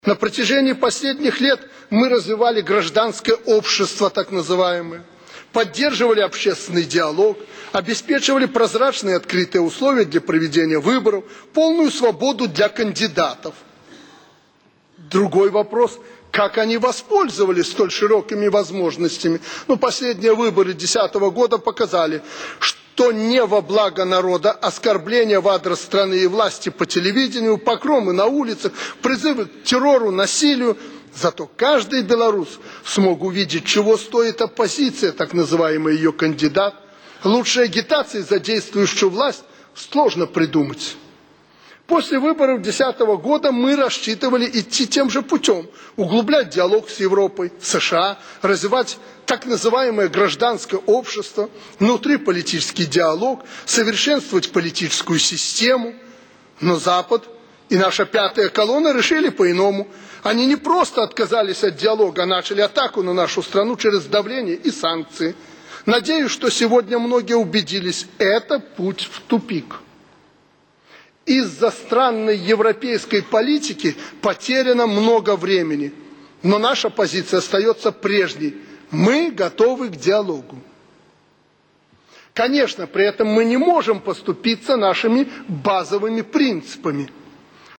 Пасланьне да беларускага народу і Нацыянальнага сходу. 8 траўня 2012